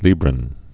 (lēbrən, lī-)